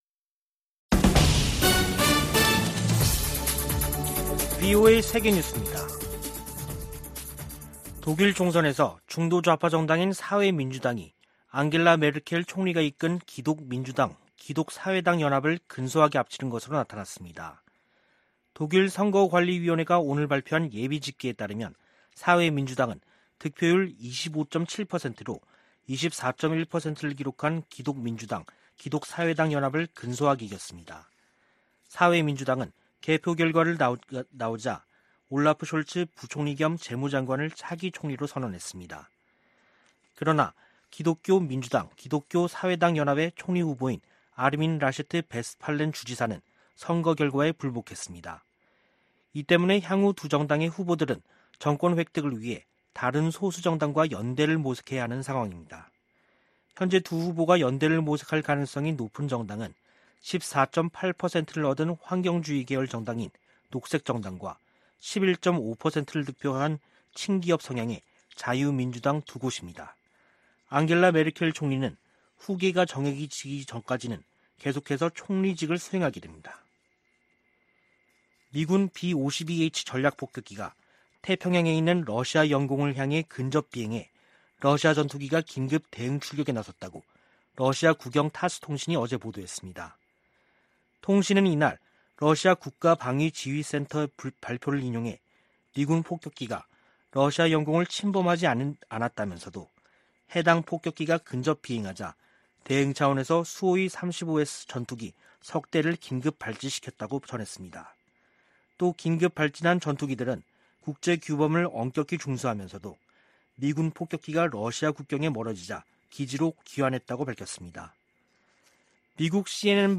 VOA 한국어 간판 뉴스 프로그램 '뉴스 투데이', 2021년 9월 27일 3부 방송입니다. 김여정 북한 노동당 중앙위원회 제1부부장이 연이틀 담화를 통해 유화적 메시지를 보내 주목됩니다. 북한은 제재 완화든 경제 발전이든 원하는 것이 있다면 협상장으로 나와야 한다고 미 국무부 부차관보가 지적했습니다. 국제원자력기구(IAEA)가 북한에 비핵화 이행을 촉구하고 국제사회의 외교적 노력을 지지하는 결의안을 채택했습니다.